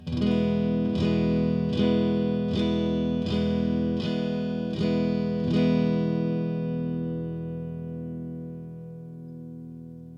down-strum.mp3